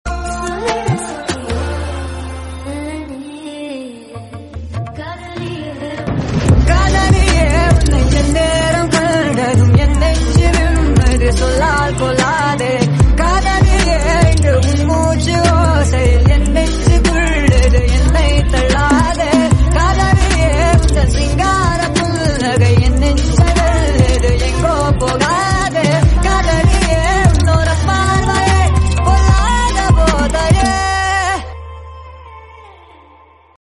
[sped up version]